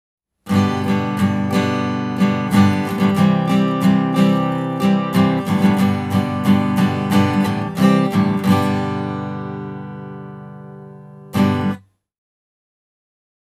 Seuraavissa audiopätkissä esitetään Zoomin stereoasetuksien vaikutusta akustisen kitaran äänityksessä. Q2HD:n etäisyys kitaraan pysyy koko ajan samana: